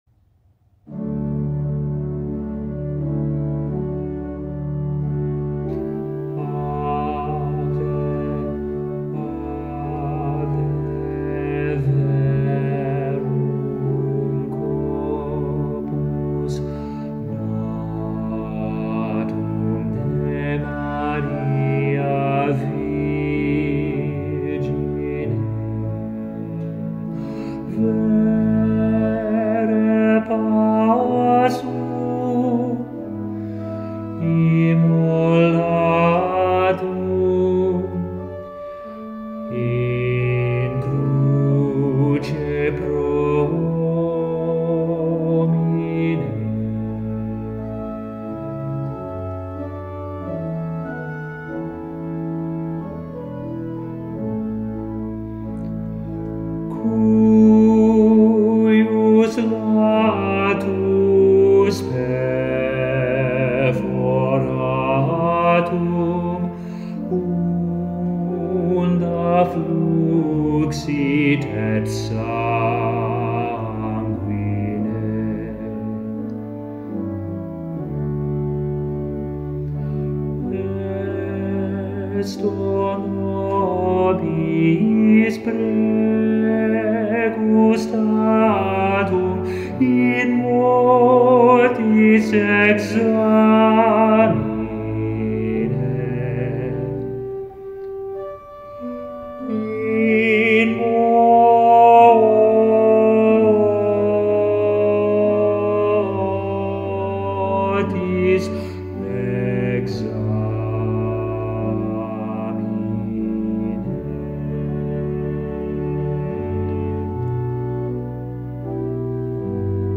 MP3 versions chantées
Basse
Ave Verum Corpus Mozart Bass Practice Mp 3